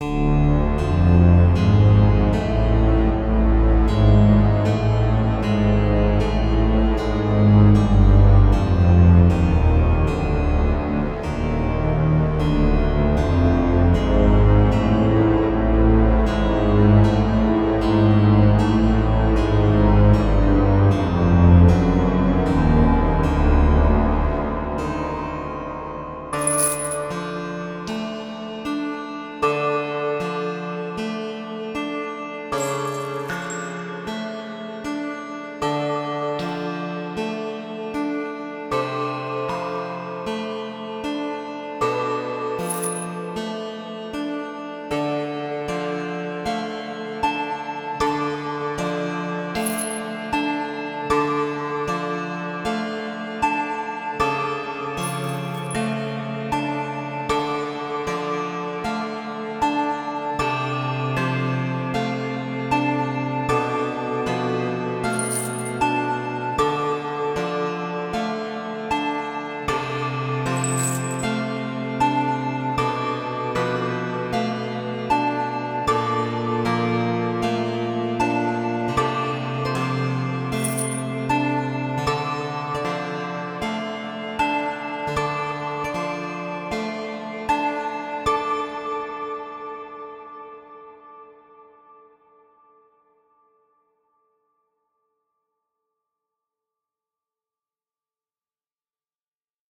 Dungeon / jail music, i guess it fits pretty well for dungeon music